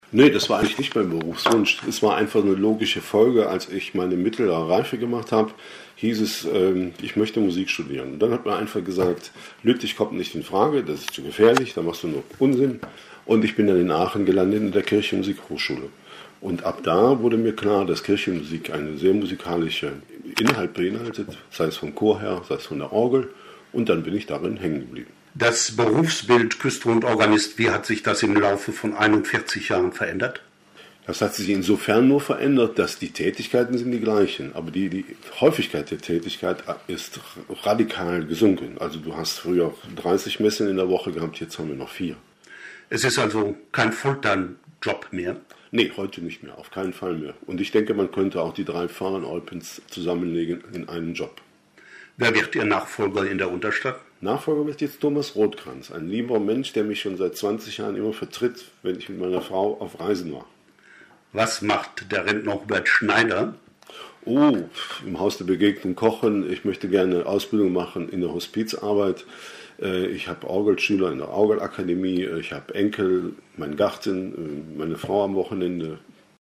Hier ein kurzer Auszug: